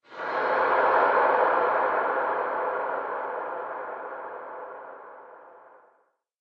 cave4.mp3